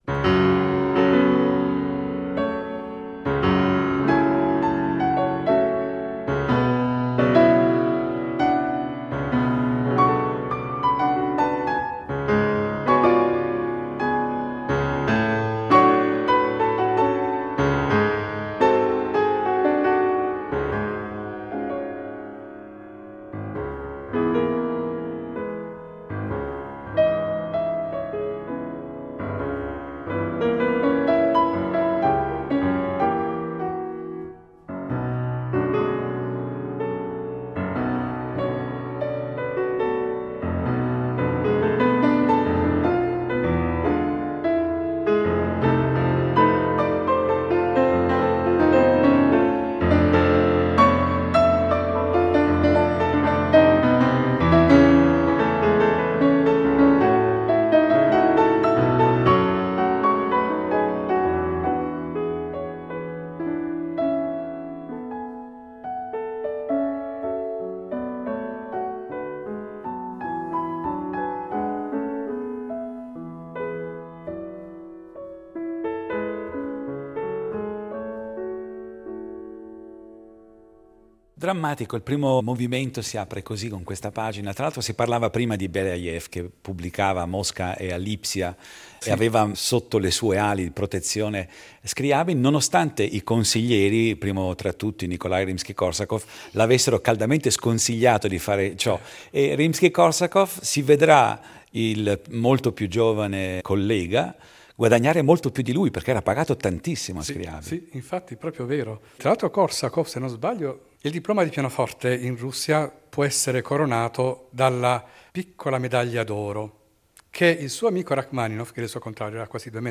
Arabesque